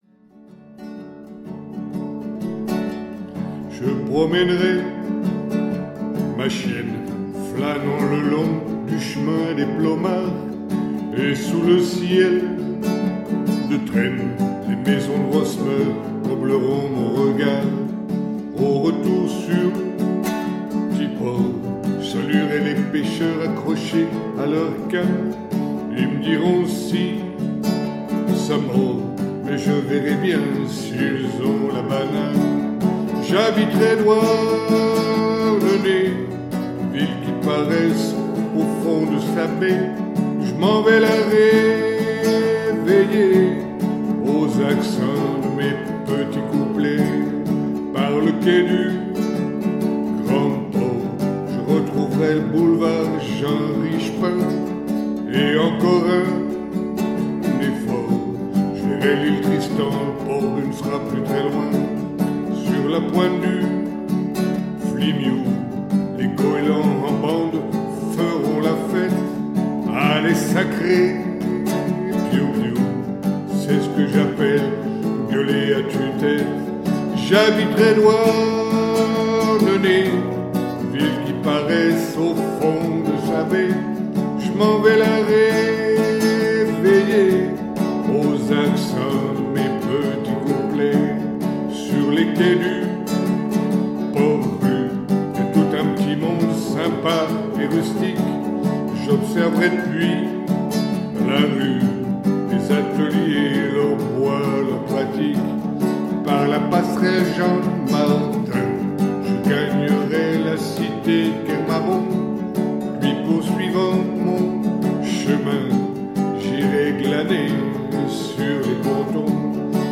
(Humour) 11 mai 2015